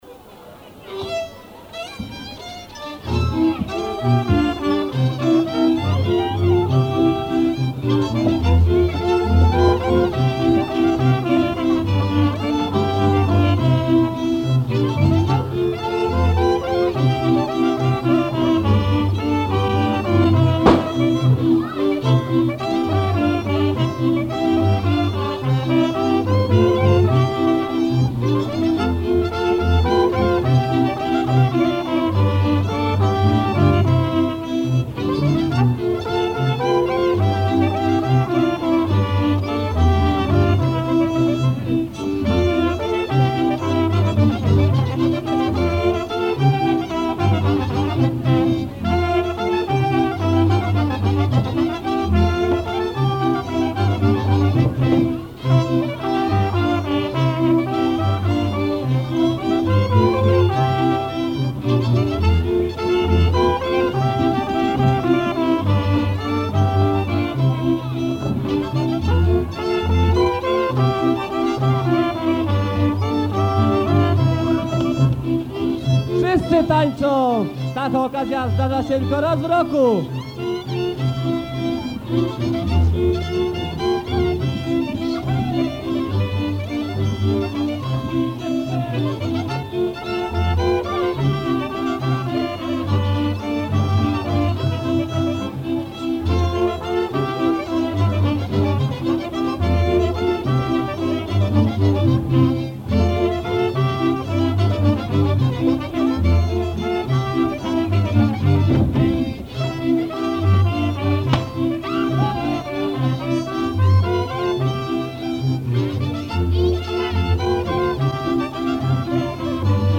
Vingtième pièce - Valse par une kapella